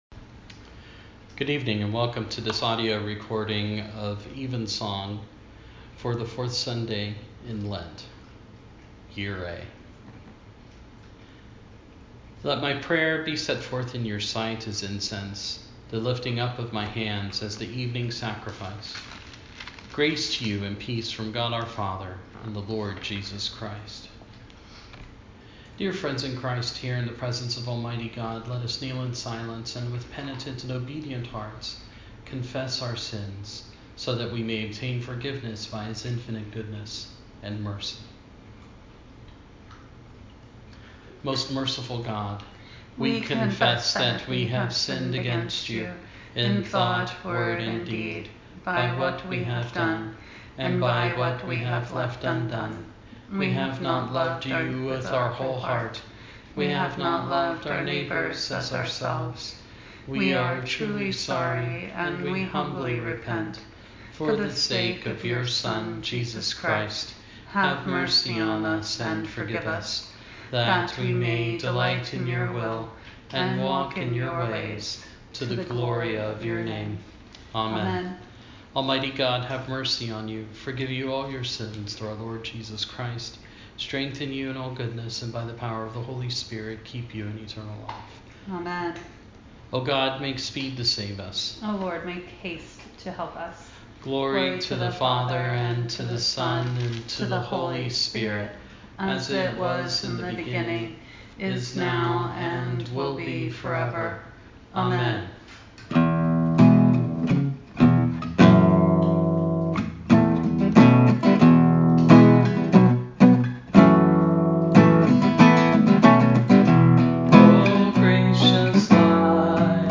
Evensong at home
evensong-3-22-20.mp3